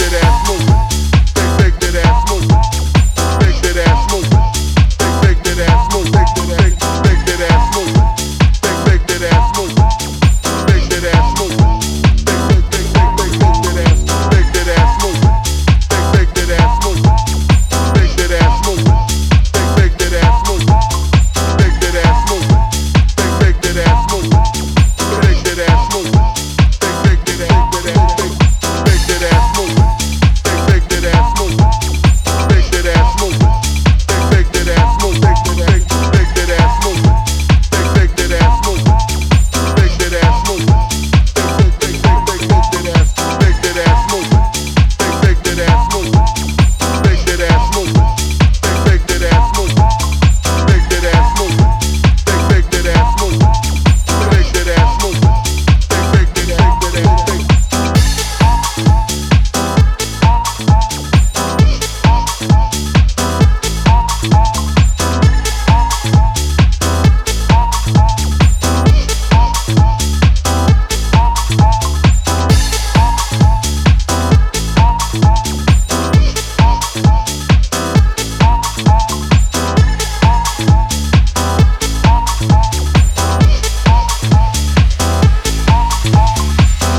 energetic and raw club-oriented EP